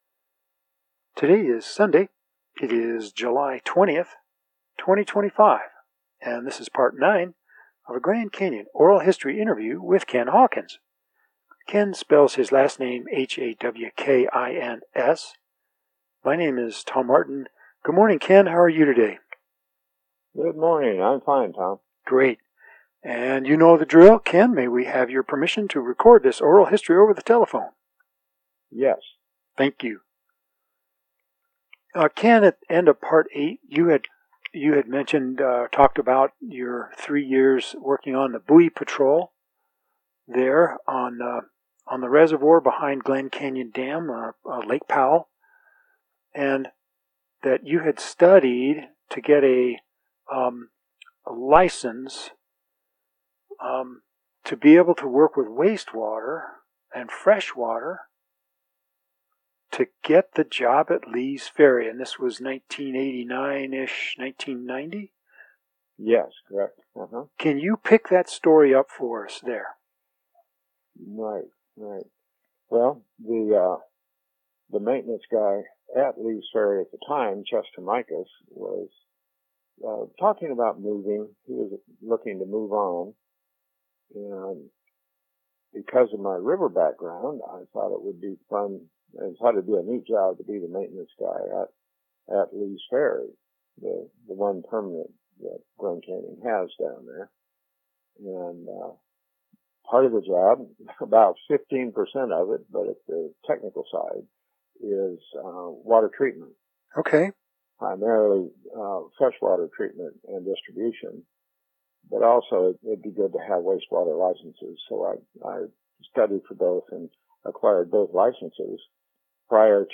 Oral Histories for last name beginning with H